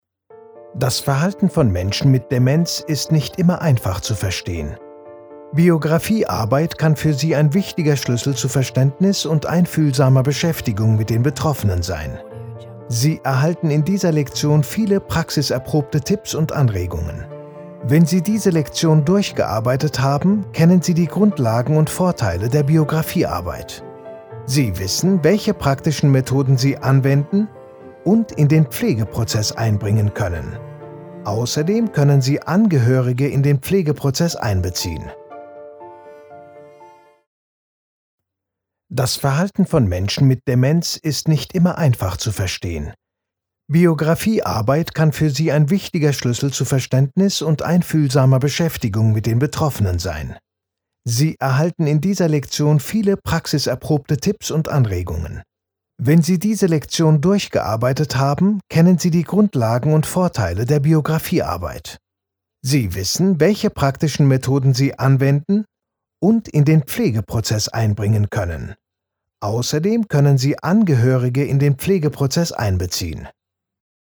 Synchronsprecher in 4 Sprachen: Deutsch, Englisch, Türkisch und Arabisch.
Kein Dialekt
Sprechprobe: eLearning (Muttersprache):